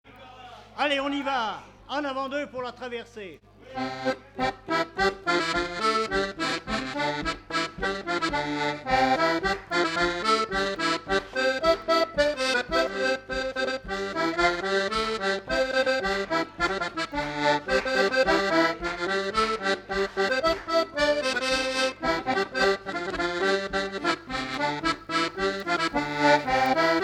Mémoires et Patrimoines vivants - RaddO est une base de données d'archives iconographiques et sonores.
danse : branle : avant-deux
Fête de l'accordéon
Pièce musicale inédite